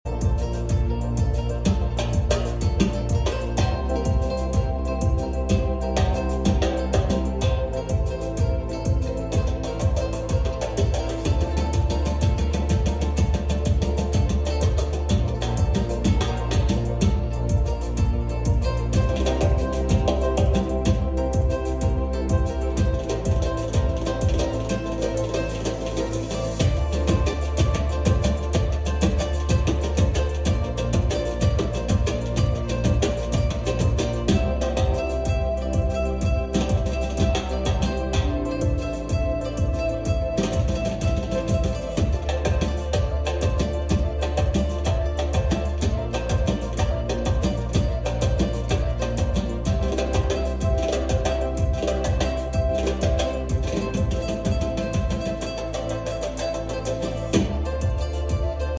Hoverboard project using BLDC motor sound effects free download